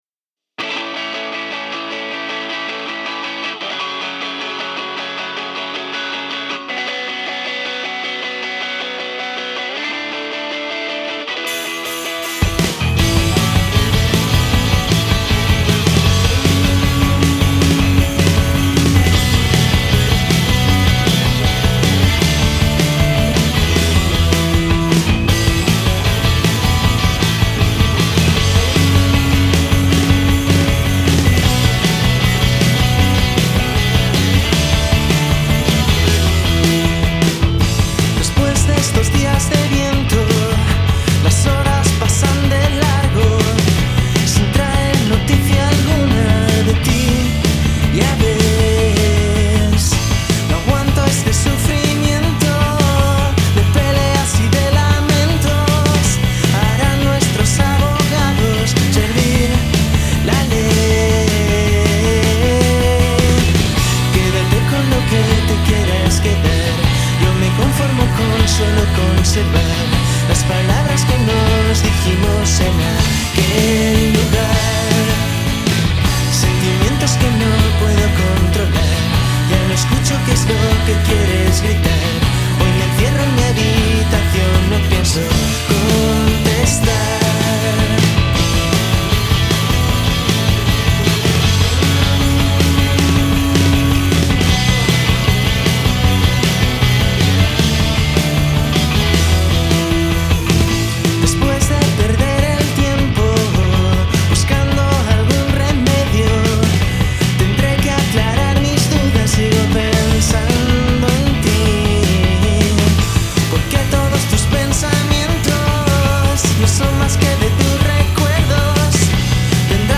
Indie-Rock
Guitarra y Voz
Bajo y Coros
Bateria y Coros